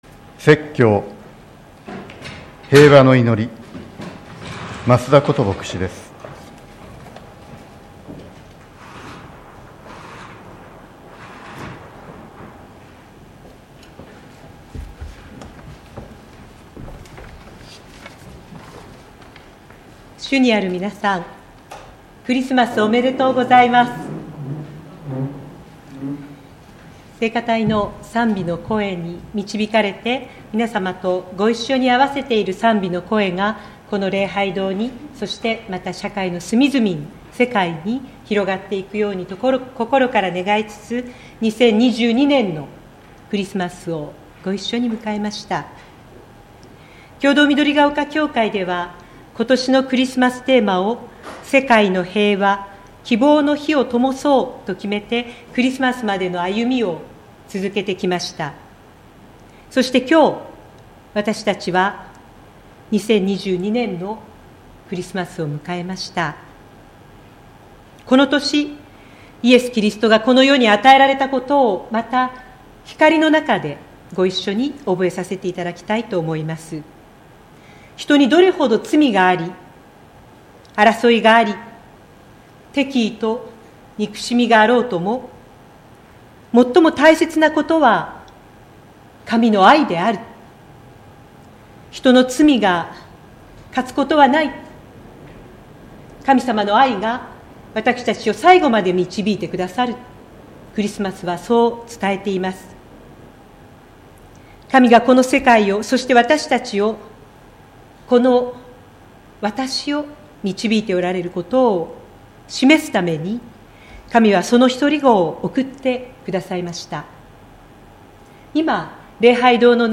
2022年12月25日（日）主日礼拝説教
クリスマス礼拝